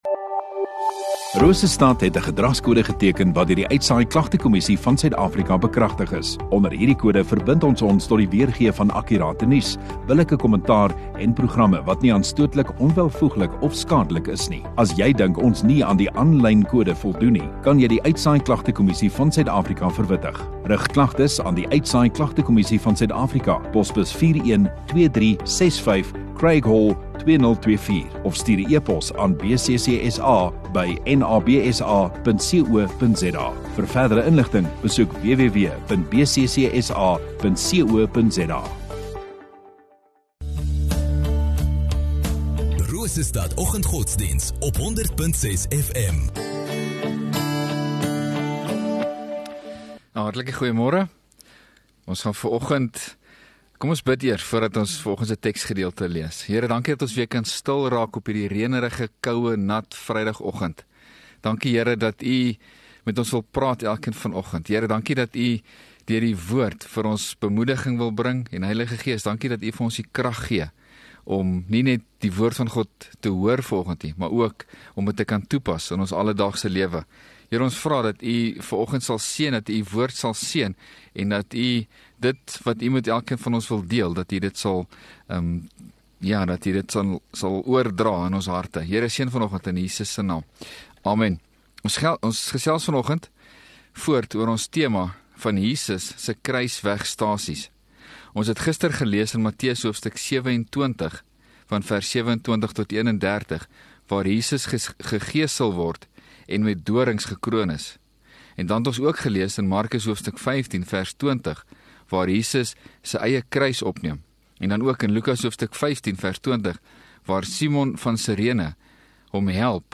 6 Mar Vrydag Oggenddiens